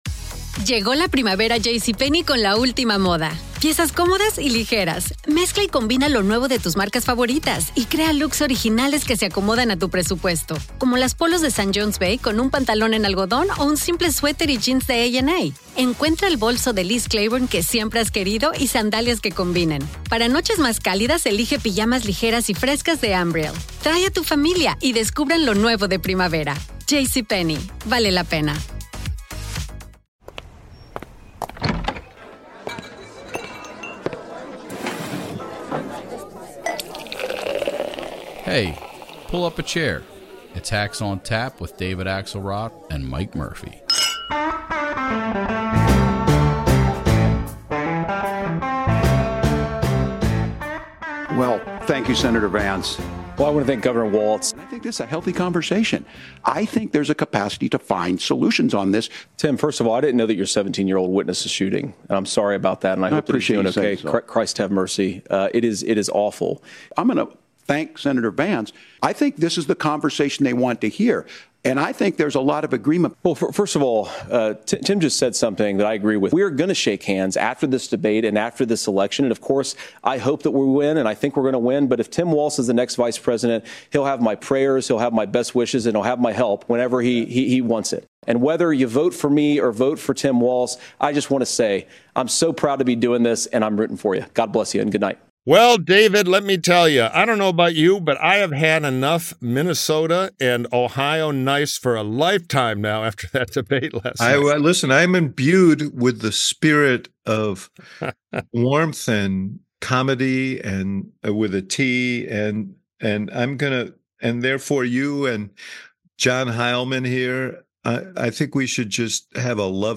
Three bleary-eyed, bloviating Hacks convened this morning to dissect the VP debate.